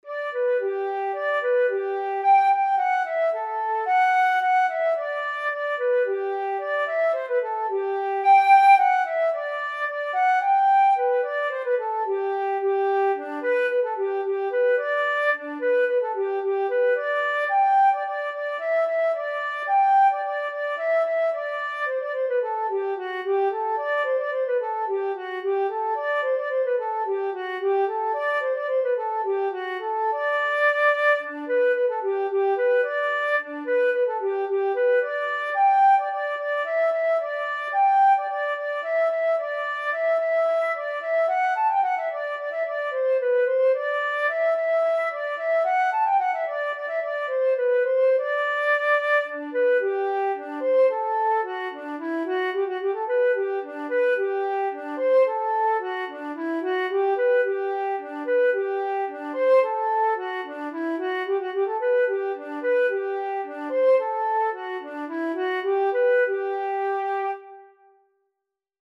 Ivanku-Ivanku-z-toho-boku-jarku_var2_v7_NOTY_Sopilka.mp3